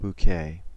Ääntäminen
US
IPA : /boʊˈkeɪ/ IPA : /buˈkeɪ/